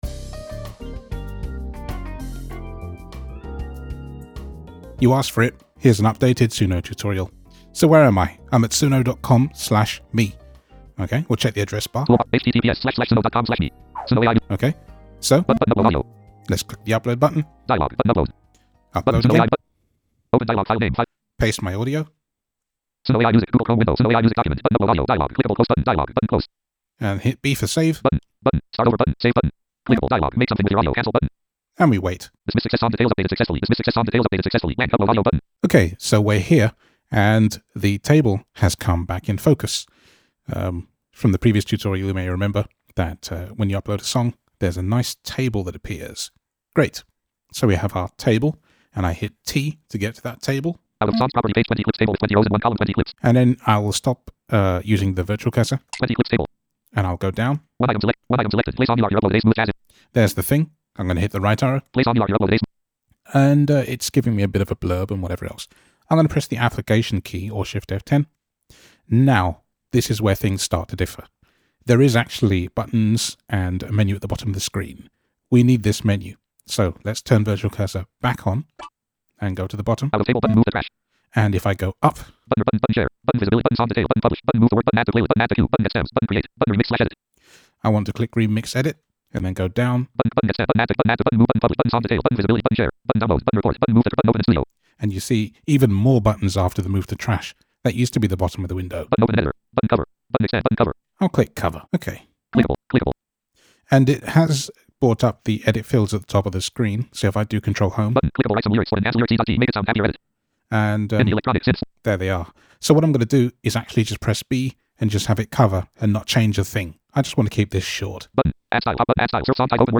Updated_Suno_Tutorial.mp3